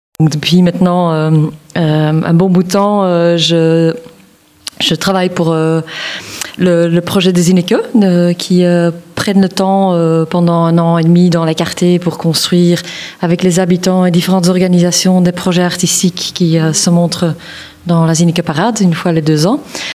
zinneke_explication.mp3